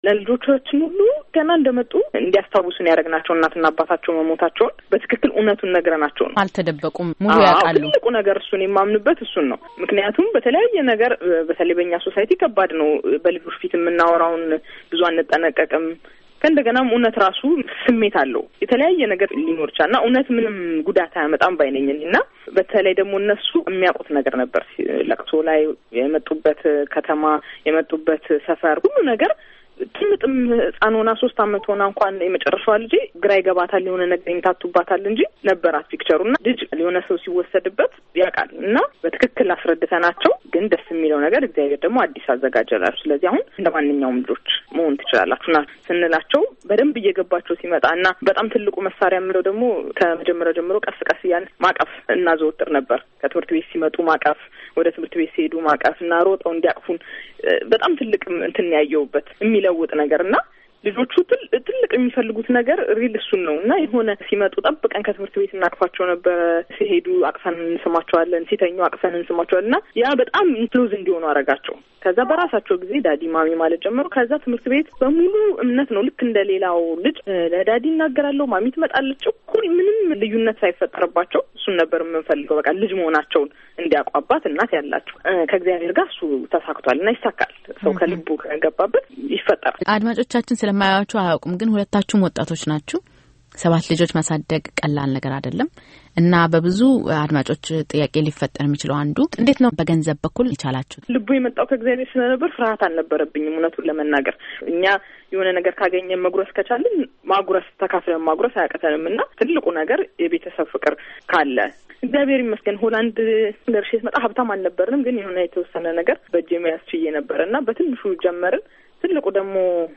Embed share የቃለምልልሱን ሁለተኛ ክፍል ከዚህ ያድምጡ by የአሜሪካ ድምፅ Embed share The code has been copied to your clipboard.